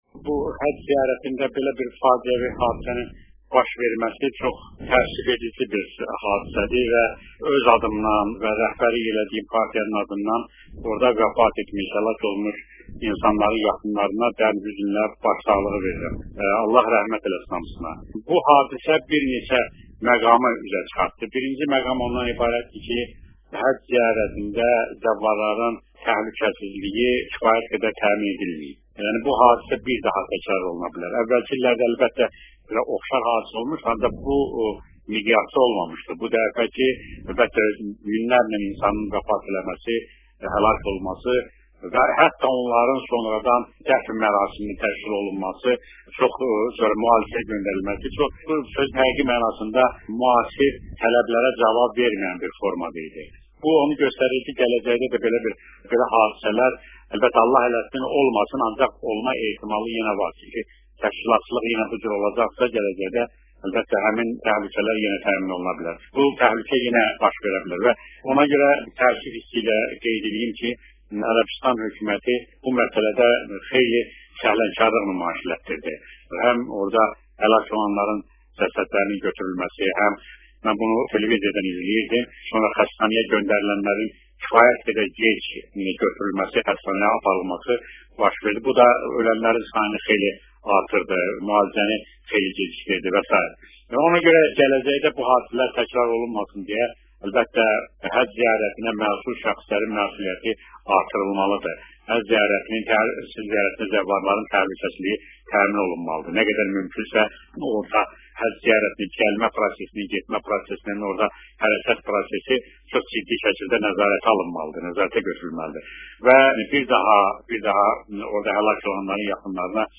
minada baş verən faciə ilə bağlı Tehran radiosunun eksklüziv müsahibədə söyləyib: